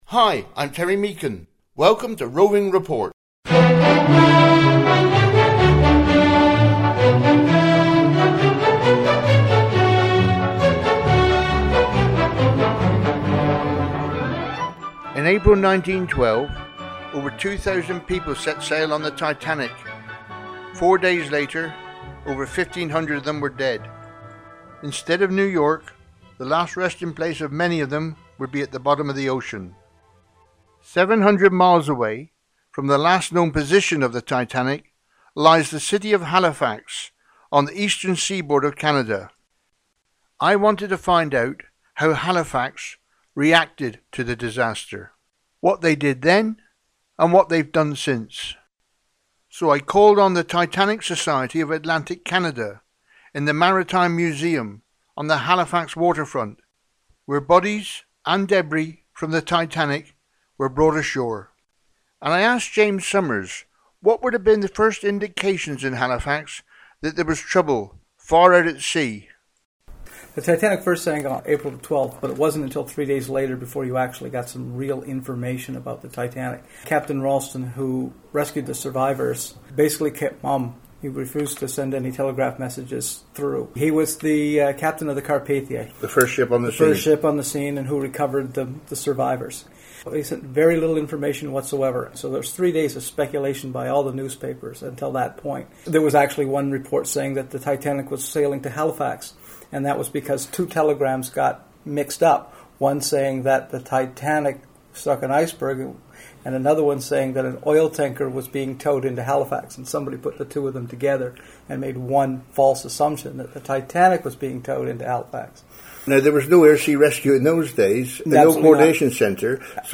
When the Titanic sank in April 1912, Halifax Nova Scotia became the last resting place for many of the passengers who perished and were hauled out of the freezing Atlantic by the cable ship Mackay-Bennett . Recorded on location in Halifax Nova Scotia, we hear first-hand from members of the Titanic Society of Atlantic Canada on how Titanic has impacted on this naval and shipping port on Canada's Eastern Seaboard
With a Running length 25 Mins, this programme is a Roving Report Special for Community Radio with no adverts and no Station ID in the ready-to-broadcast files Leave Comment